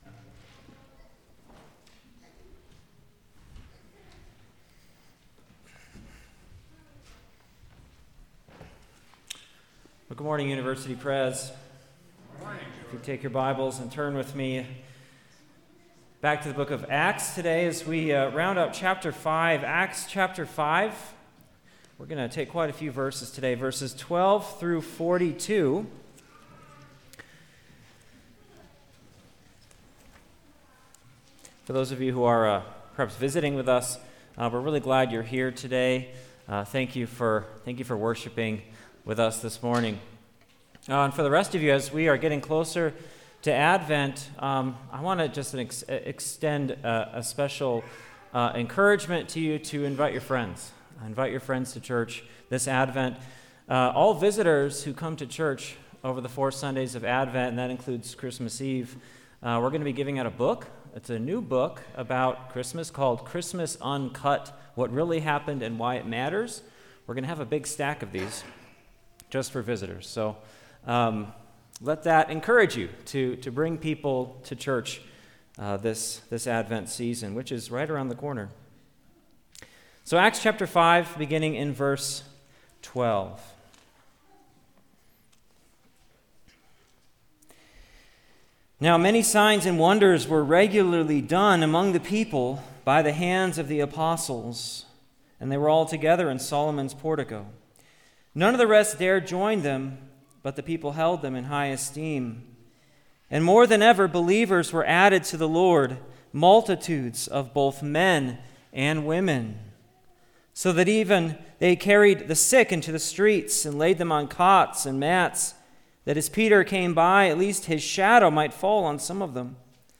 Acts Passage: Acts 5:12-42 Service Type: Sunday Worship « The Resurrection Economy Shepherds